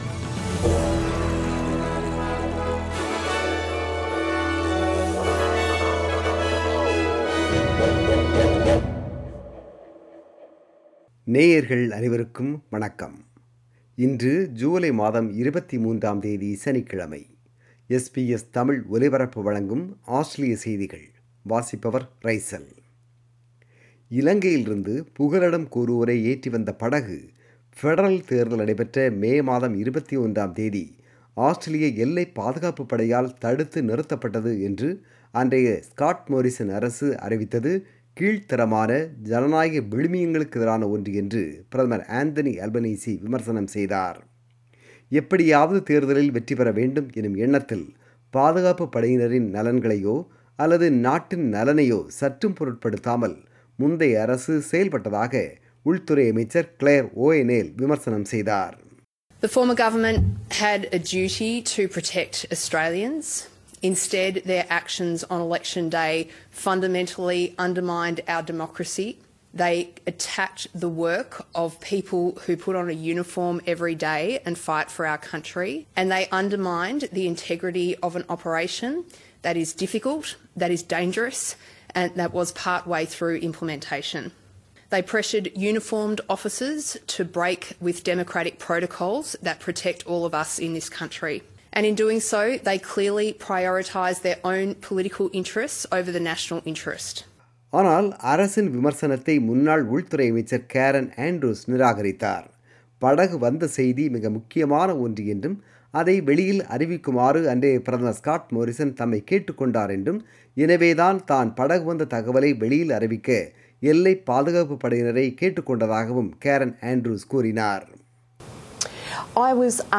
Australian News: 23 July 2022 – Saturday